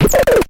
电池玩具猕猴桃" 鼓高2
描述：从一个简单的电池玩具中录制的，是用一个猕猴桃代替的音调电阻！
Tag: 音乐学院-incongrue 电路弯曲 俯仰